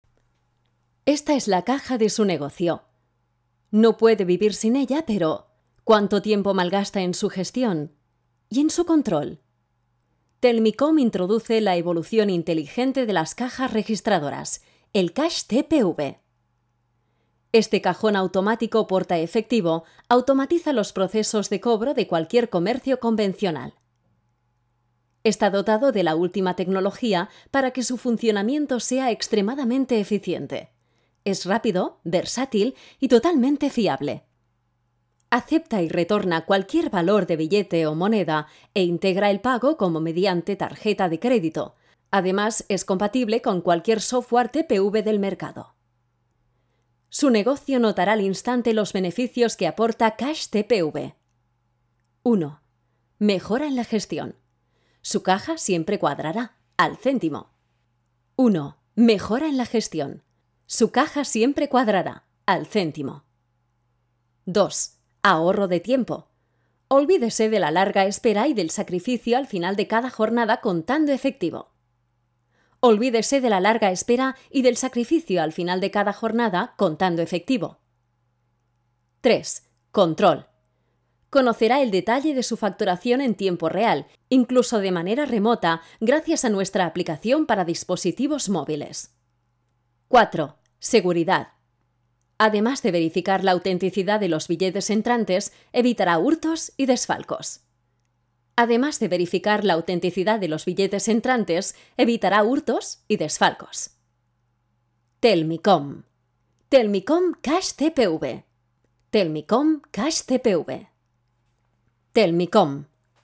kastilisch
Sprechprobe: Sonstiges (Muttersprache):
I do not notice any accent.
VOZ PARA ANIMACIÓN.mp3